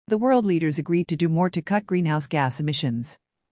このままの速度でお聞きください。
【ノーマル・スピード】